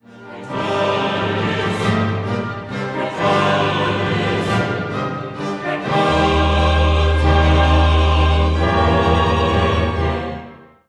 The scoring is for two violins, bass, and organ continuo with bassoons doubling the bass, two trumpets, timpani, two horns, two oboes, and 3 trombones doubling the alto, tenor, and bass vocal parts.
The chorus sing Theme 6 at “Qui tollis peccata mundi“, which sort of curves up to fit against the soloists’ Theme 7 at “Miserere nobis”.